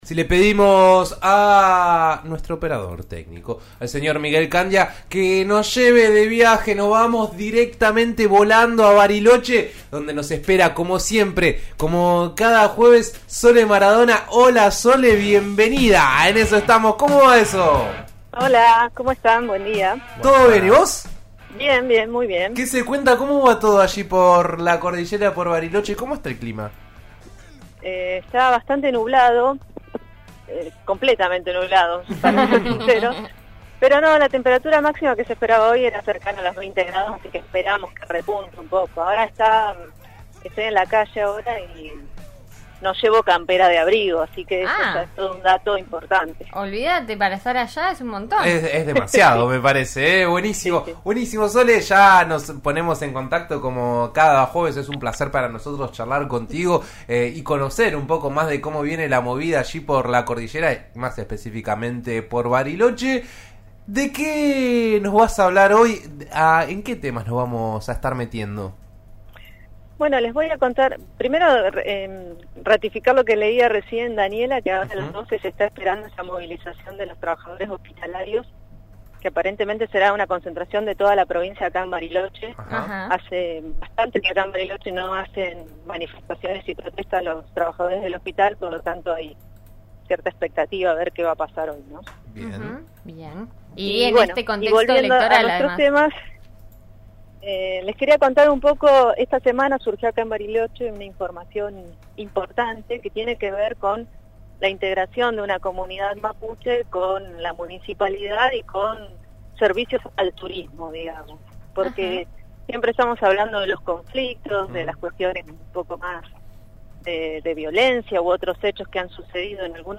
Escuchá la columna completa: